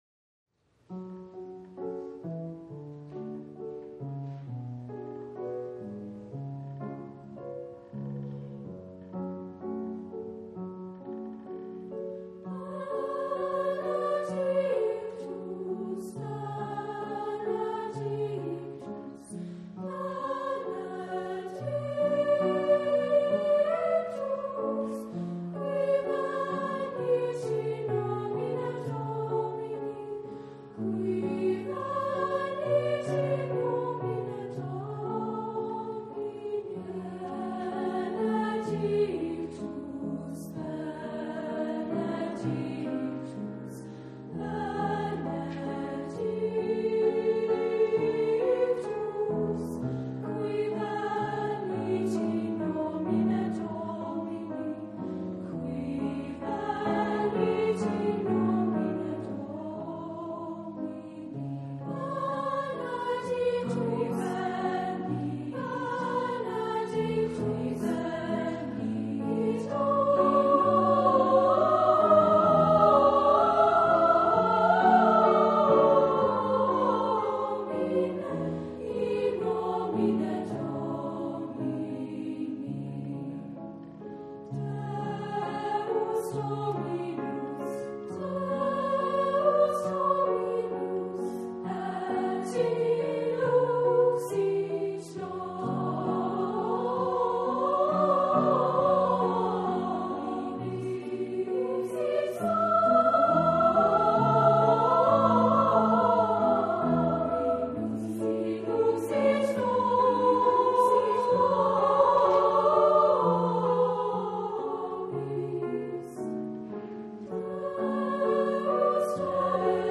Voicing: SA